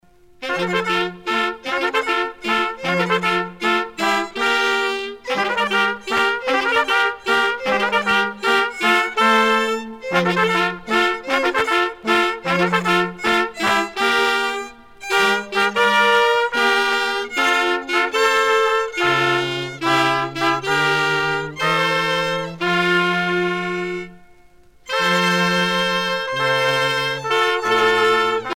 danse-jeu : trompeuse
groupe folklorique
Pièce musicale éditée